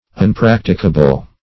Unpracticable \Un*prac"ti*ca*ble\, a.
unpracticable.mp3